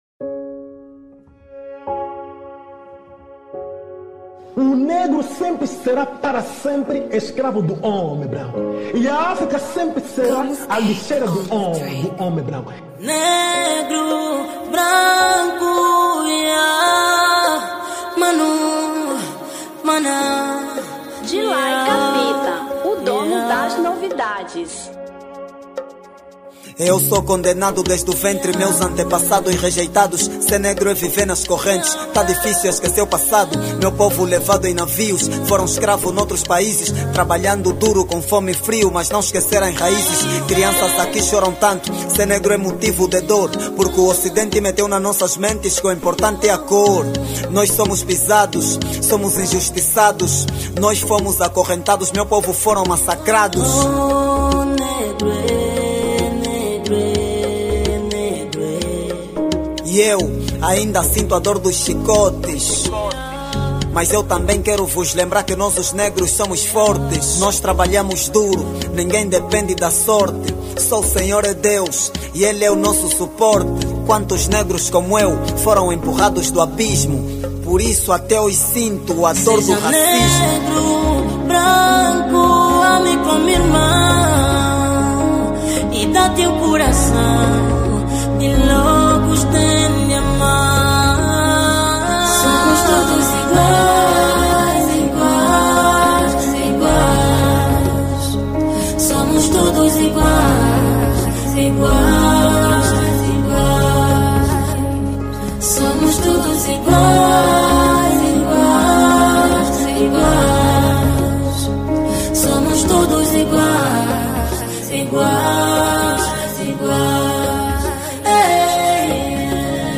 Kuduro 2025